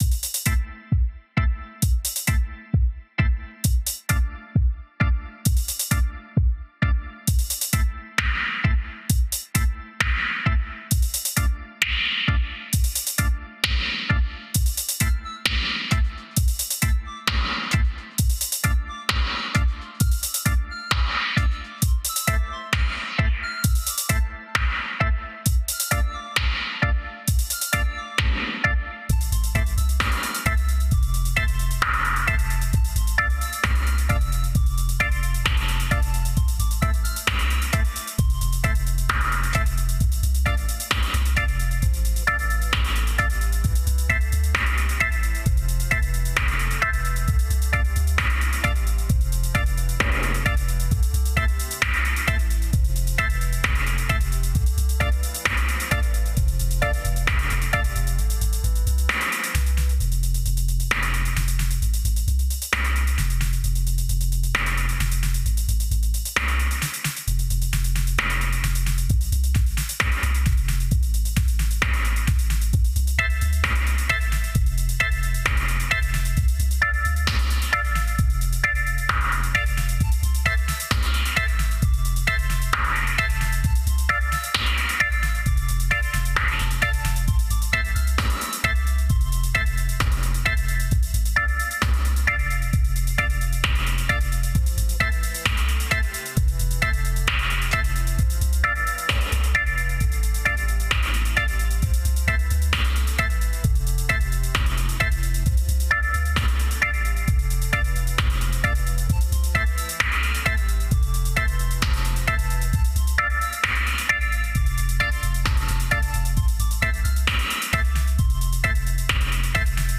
Tryed making changes to baseline and eq, since in ear phones and speakers dont set a studio standard the outcome is duable but still not perfect